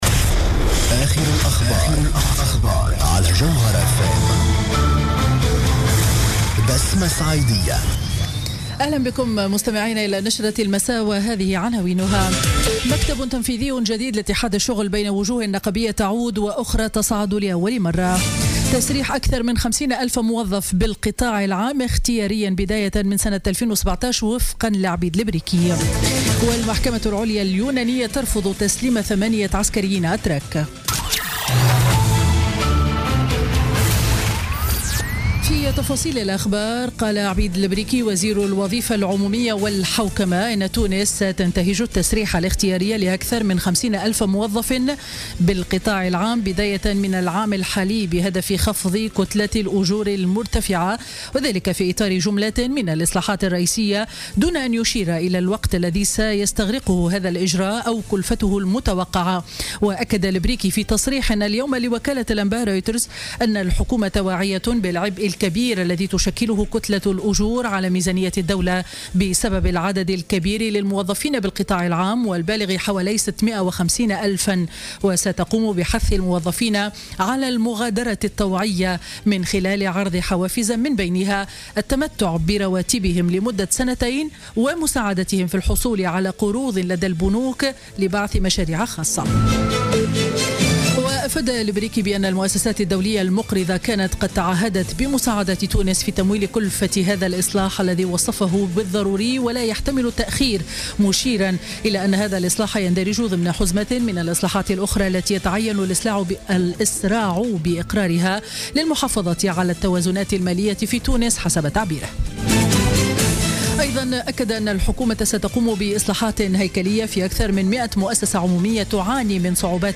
نشرة أخبار السابعة مساء ليوم الخميس 26 جانفي 2017